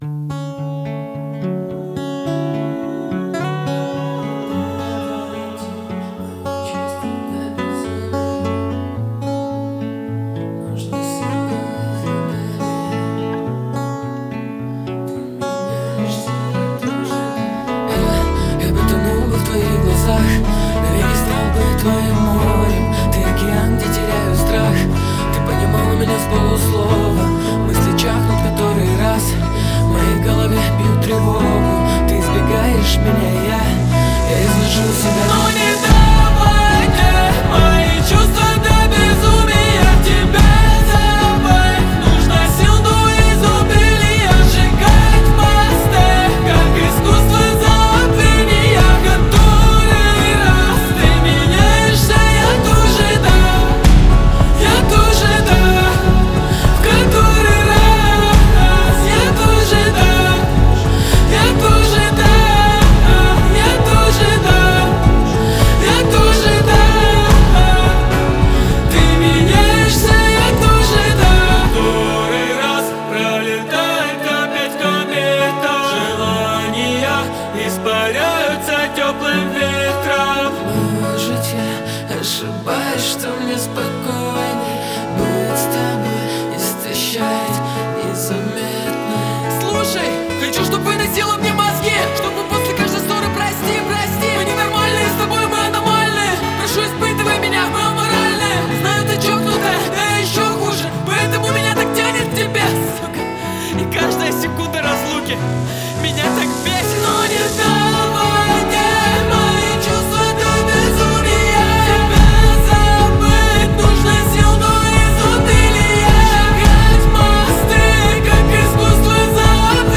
• Качество MP3: 320 kbps, Stereo